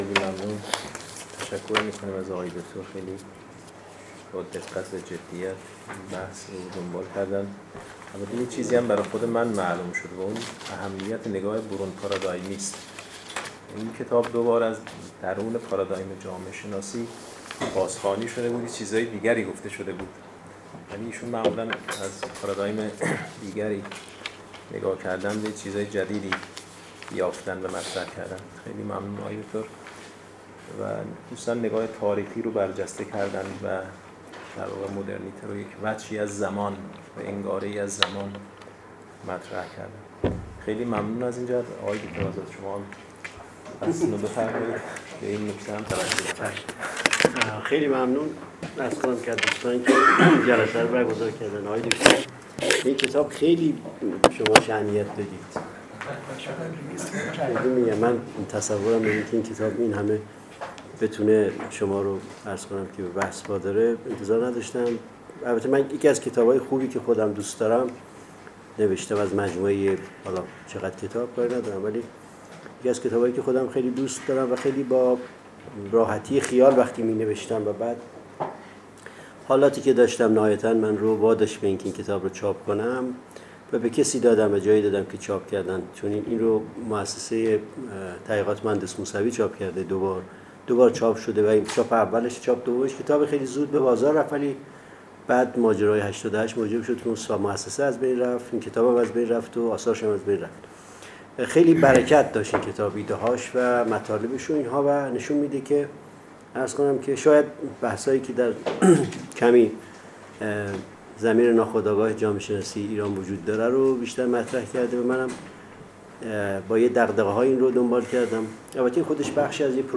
فرهنگ امروز: فایل حاضر سخنرانی «تقی آزادارمکی» درباره کتاب «علم و مدرنیته ایرانی» است که به همت انجمن جامعهشناسی ایران دی ماه ۹۳ در این مرکز برگزار شد.